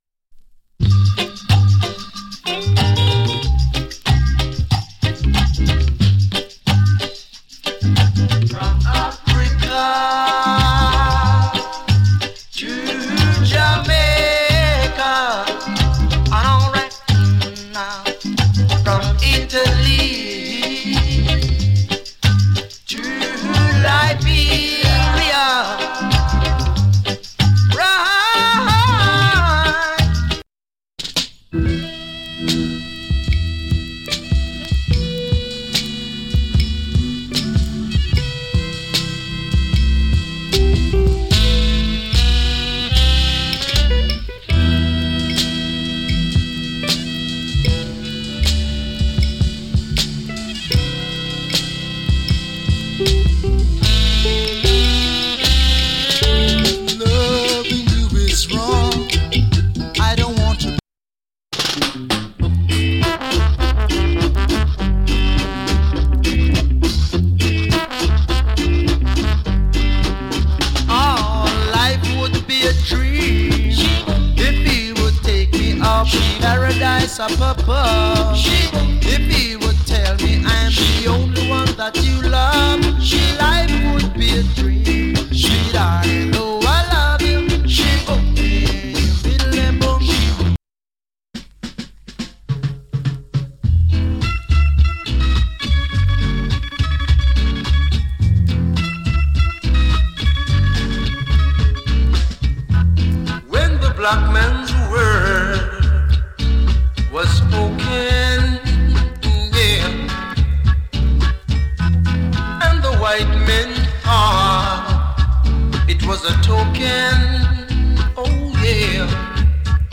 KILLER TUNE から甘い VOCAL の REGGAE や ROCK STEADY まで有り !!